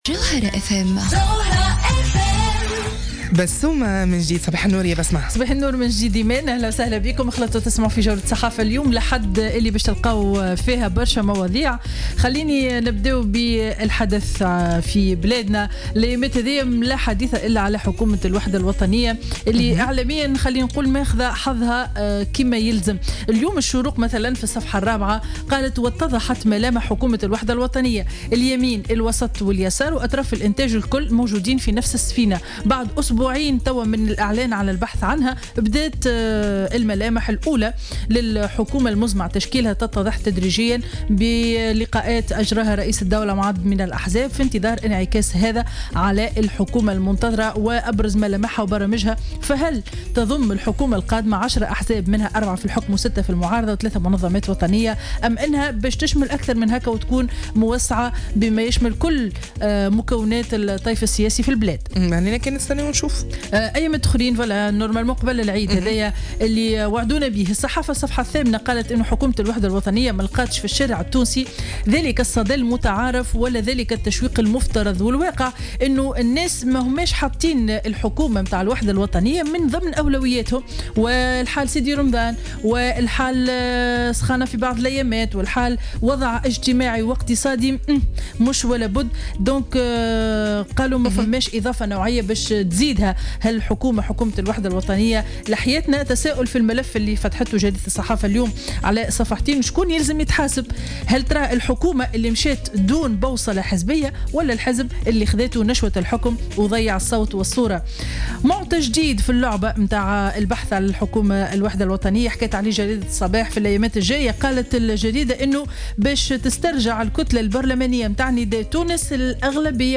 Revue de presse du Dimanche 19 Juin 2016